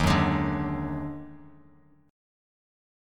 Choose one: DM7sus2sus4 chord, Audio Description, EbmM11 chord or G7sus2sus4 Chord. EbmM11 chord